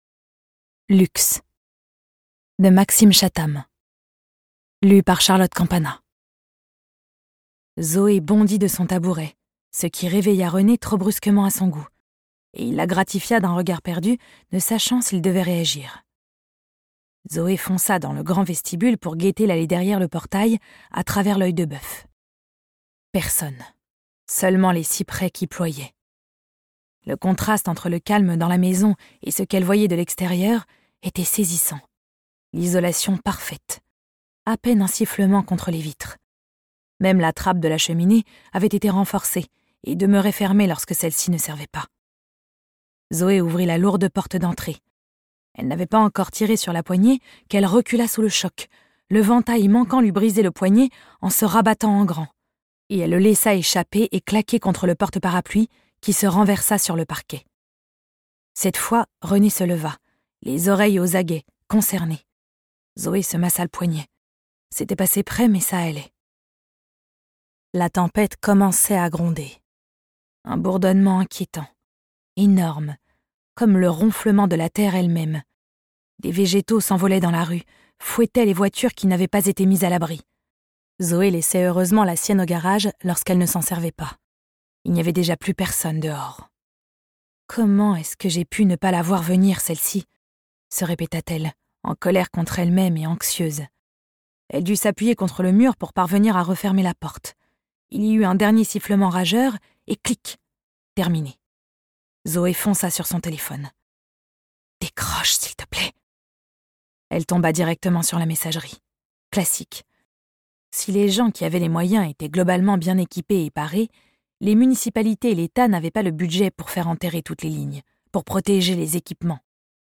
Titre Texte intégral Auteur Chattam